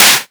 edm-clap-26.wav